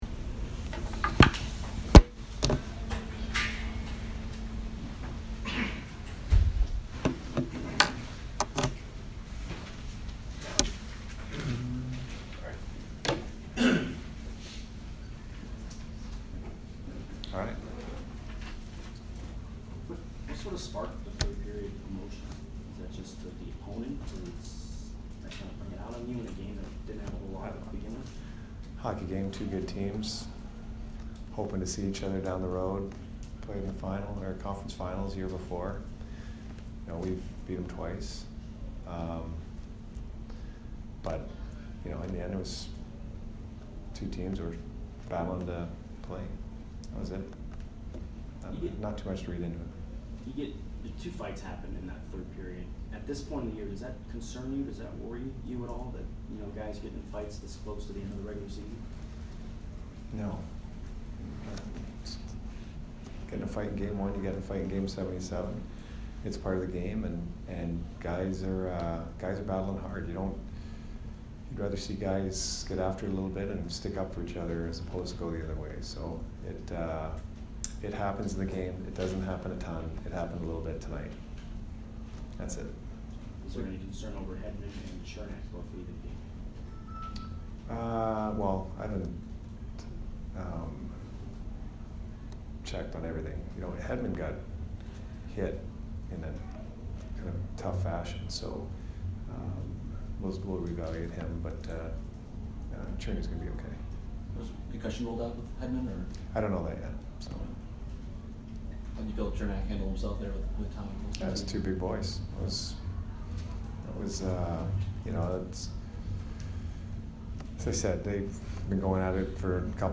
Jon Cooper post-game 3/30